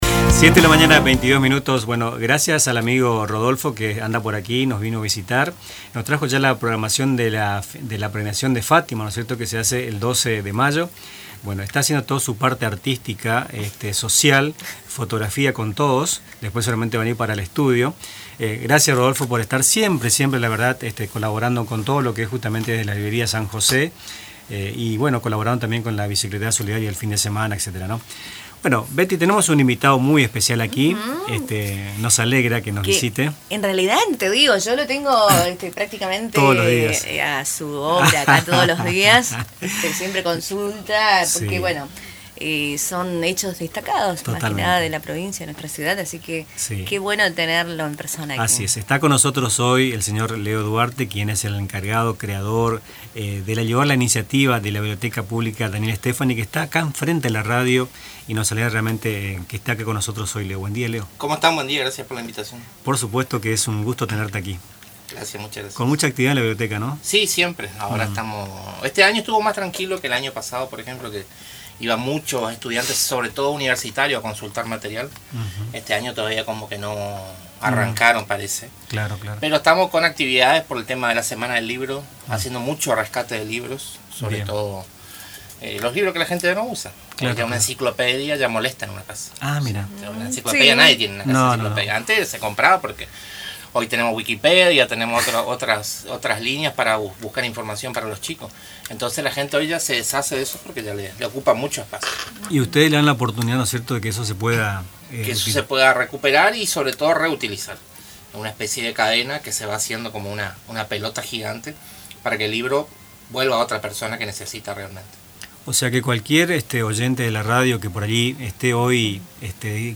entrevista